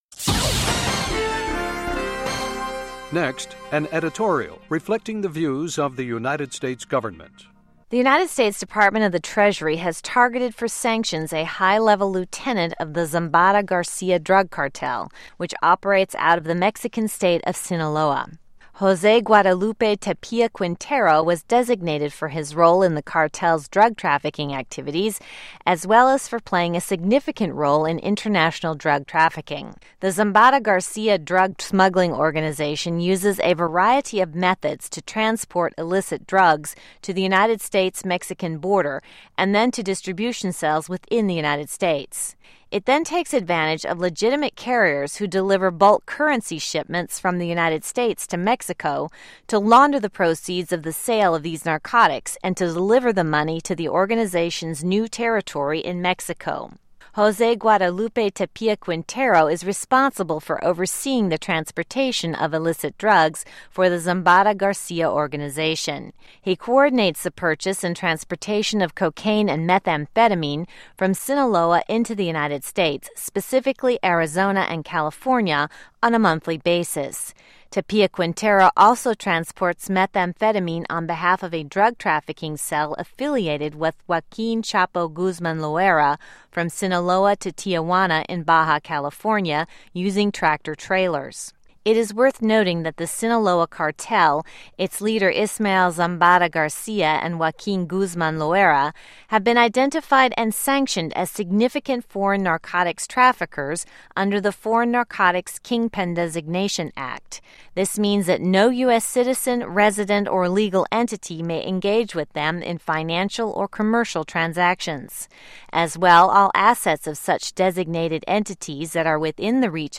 Reflecting the Views of the U.S. Government as Broadcast on The Voice of America